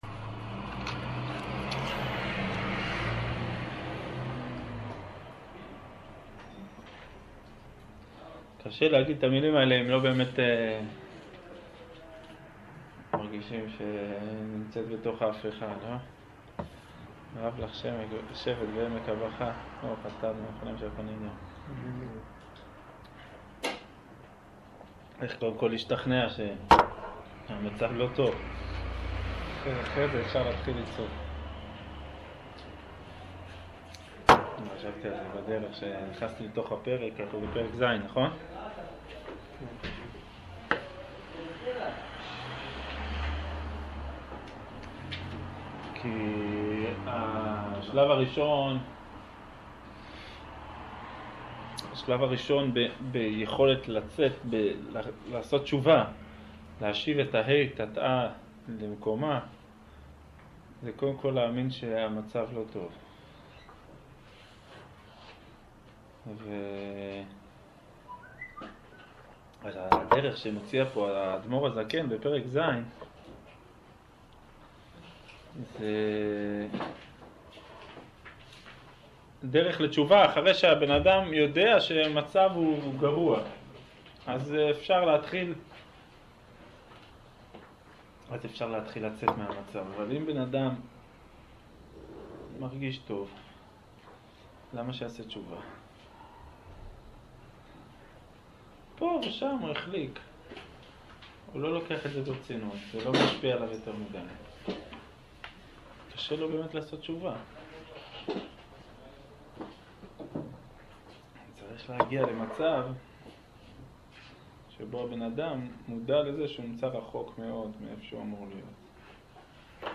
שיעור אגרת התשובה